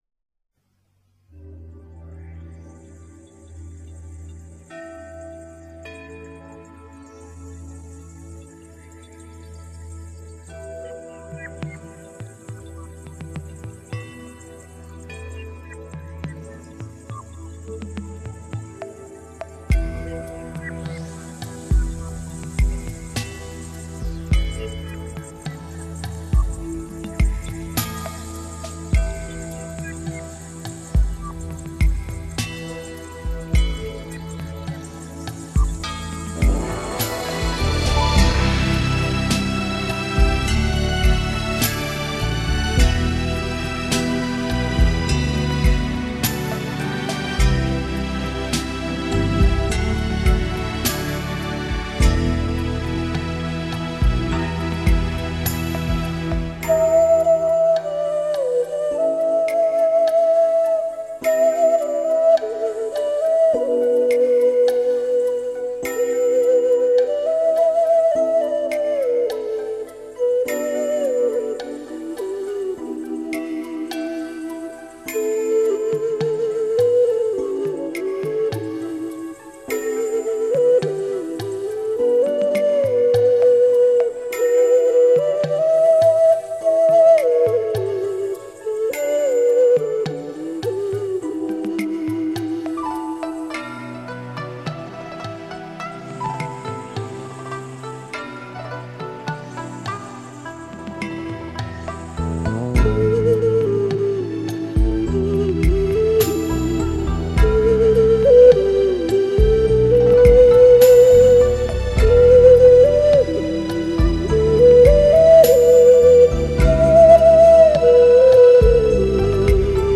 埙演奏
埙独奏